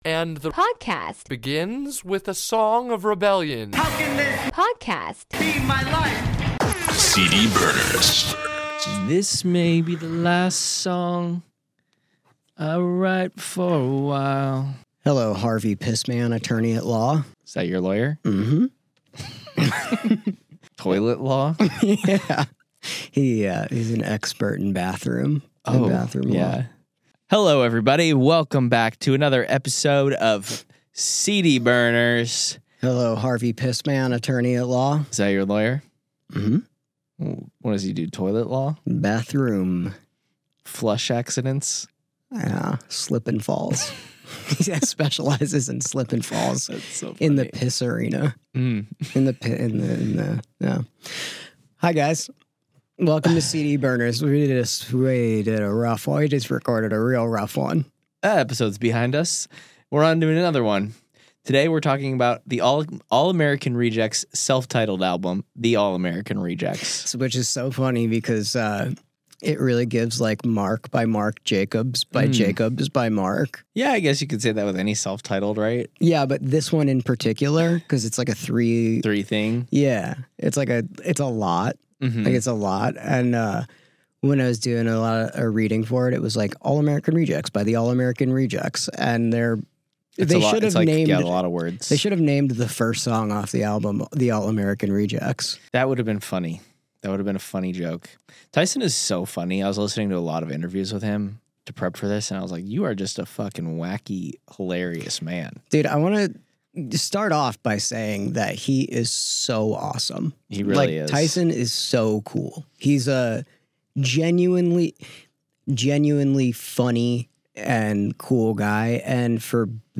The guys chat with Nick and Chris about the process of making this album and what it was like recording with Doghouse records at the ripe age of 17 and 19.